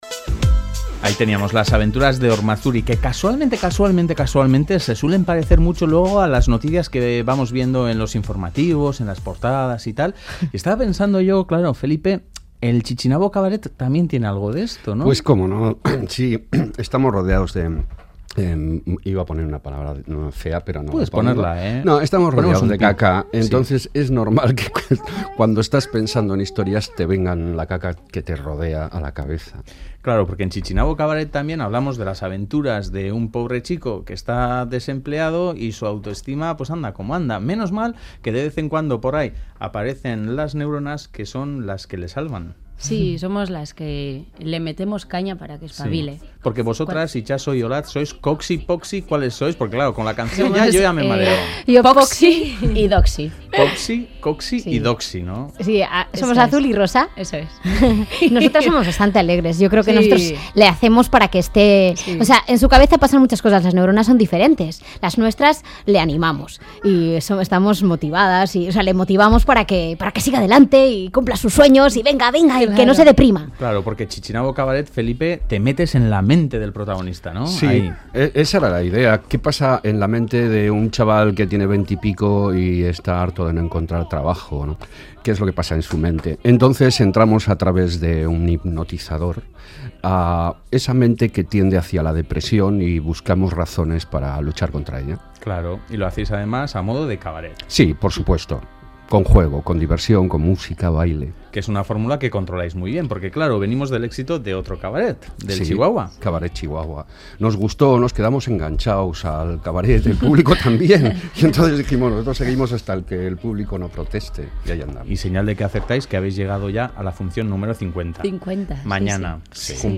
Audio: Chichinabo Cabaret llega a su pase número 50, lo cual será motivo de celebración en Pabellón 6. Hablamos con algunos de los protagonistas.